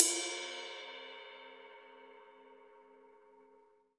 Index of /90_sSampleCDs/USB Soundscan vol.10 - Drums Acoustic [AKAI] 1CD/Partition C/02-GATEKIT 2